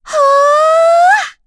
Yuria-Vox_Casting2.wav